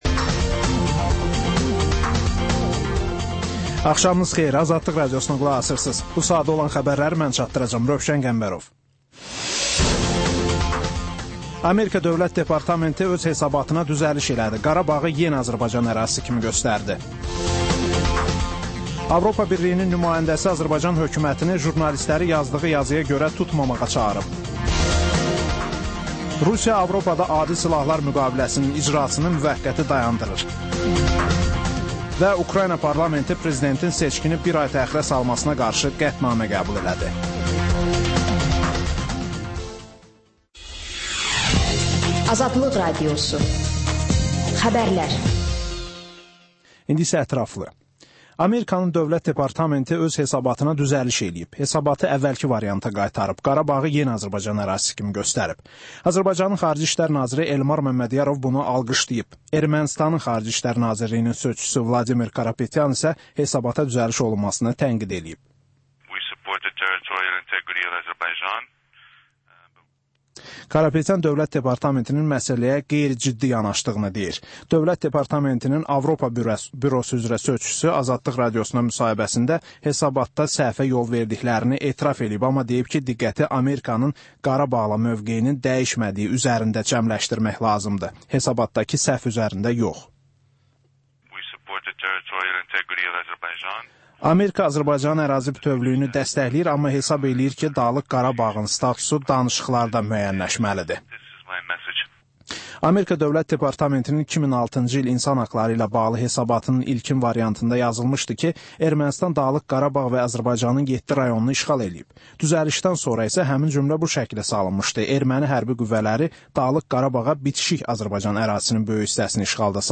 Xəbərlər, müsahibələr, hadisələrin müzakirəsi, təhlillər, sonra TANINMIŞLAR rubrikası: Ölkənin tanınmış simalarıyla söhbət